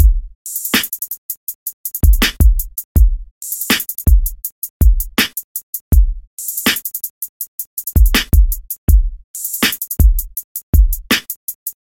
描述：第二声雨
声道立体声